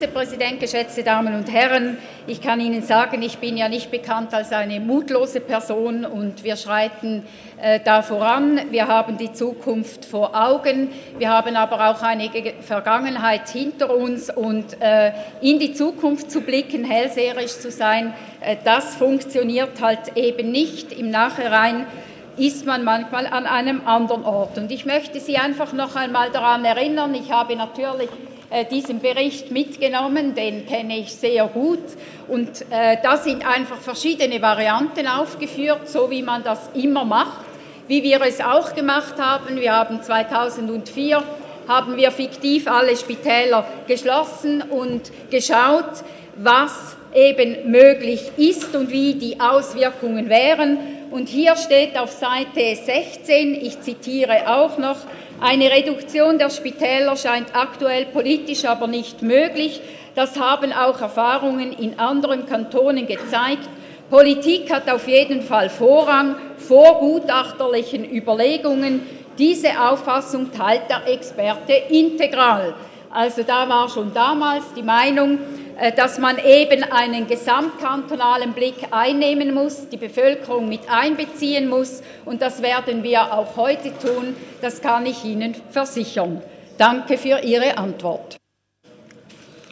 27.11.2018Wortmeldung
Session des Kantonsrates vom 26. bis 28. November 2018